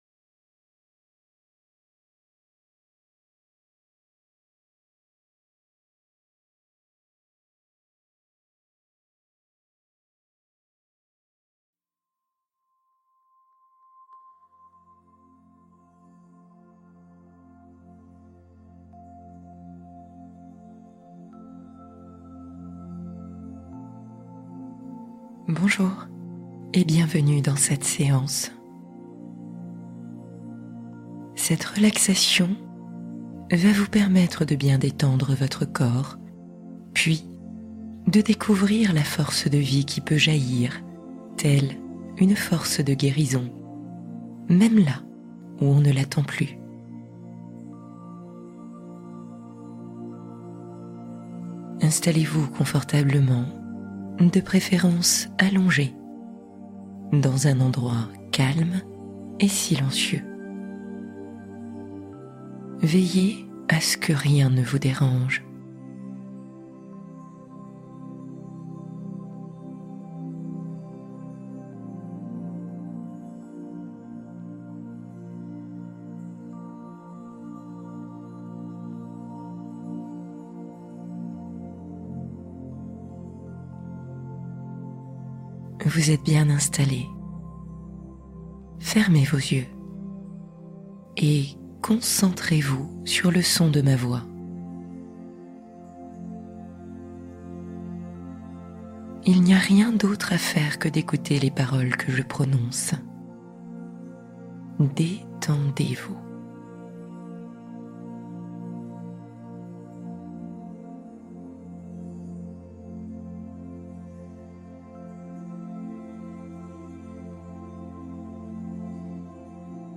Découvrez votre refuge intérieur secret | Méditation de relaxation profonde